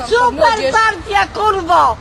Worms speechbanks
BRILLIANT.wav